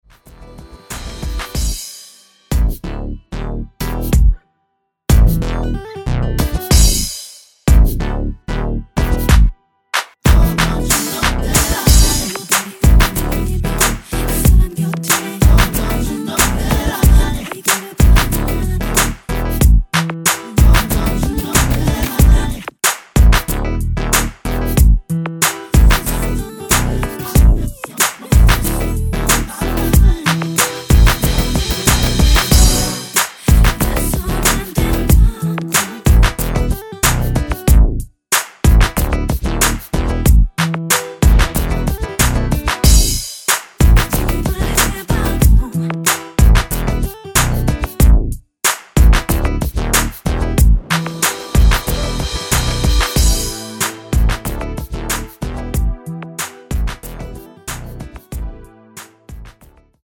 원키 코러스 포함된 MR입니다.(미리듣기 참조)
Em
앞부분30초, 뒷부분30초씩 편집해서 올려 드리고 있습니다.